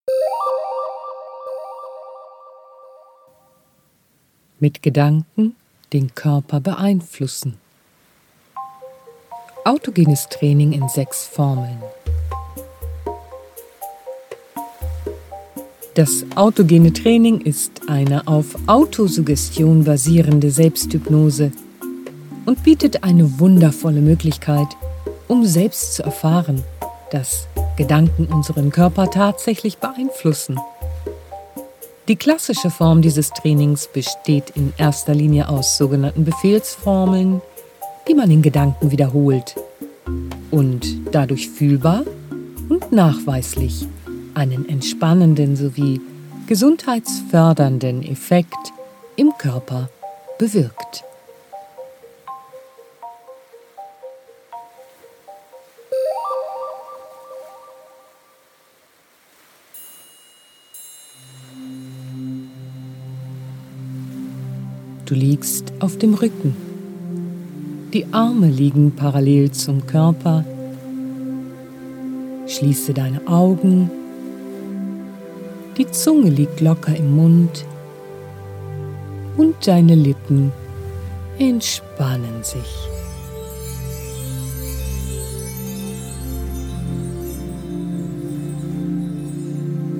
Durch die professionelle sprachliche Führung sind die Entspannungstechniken für Anfänger und Fortgeschrittene gleichermaßen geeignet. Das effektive Sounddesign vereint fein abgestimmte Entspannungsmusik und dreidimensional aufgenommene Naturklänge - und hilft Ihnen, sich von den Anspannungen des Alltags zu lösen, Stress abzubauen und eine wohltuende Tiefenentspannung zu erreichen.
Für einen optimalen Effekt empfehlen wir das Hören über Kopfhörer.